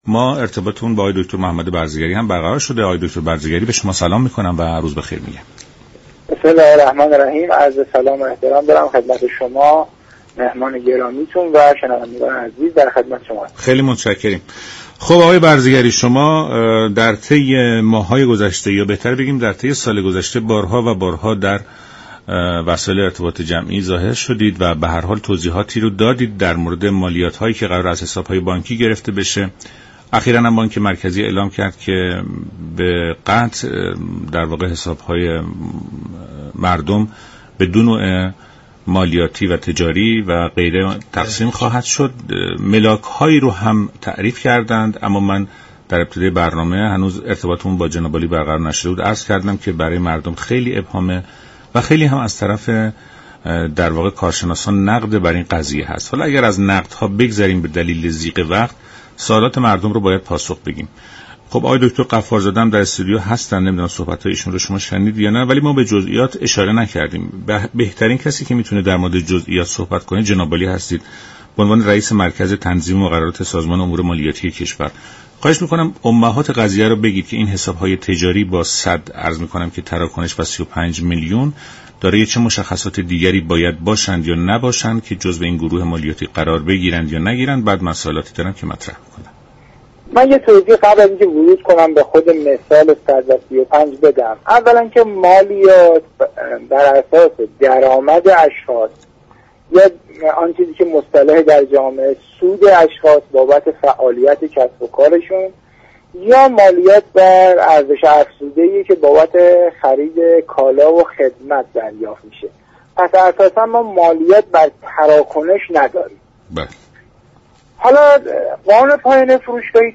برنامه «ایران امروز» شنبه تا چهارشنبه هر هفته ساعت 11:45 از رادیو ایران پخش می شود.